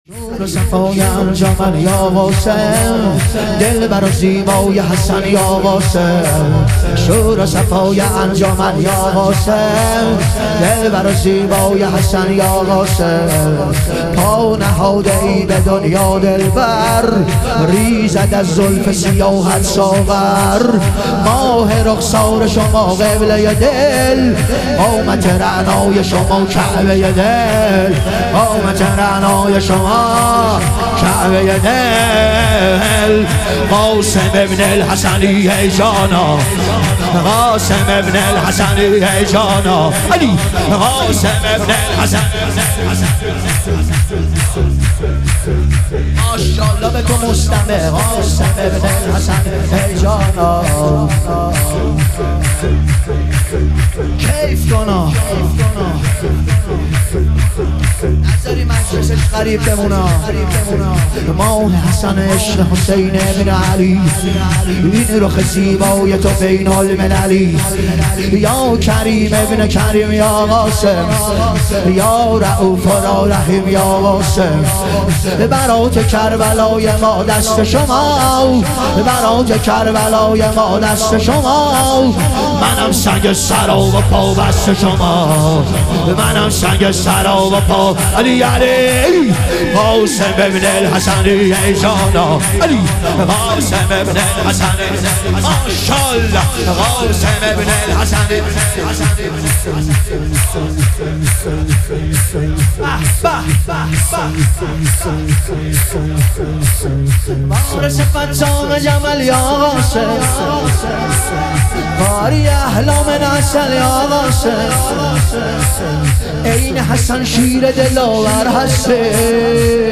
ظهور وجود مقدس حضرت قاسم علیه السلام - شور